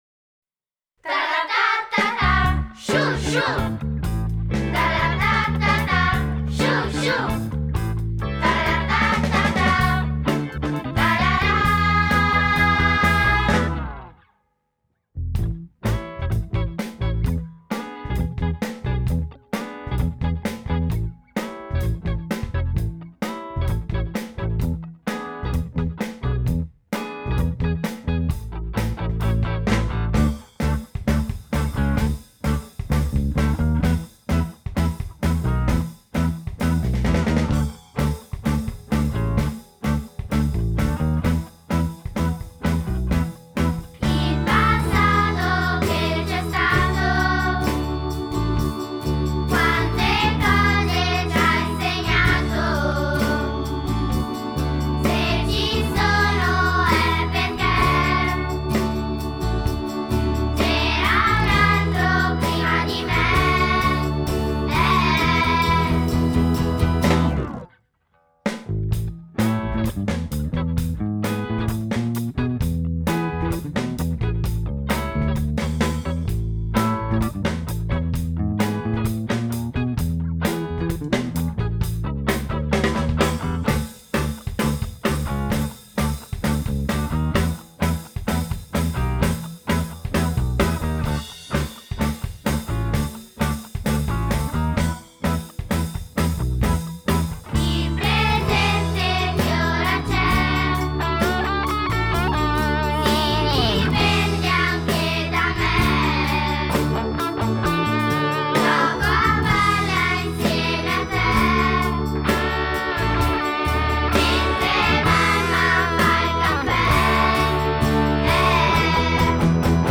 Ascolta la base musicale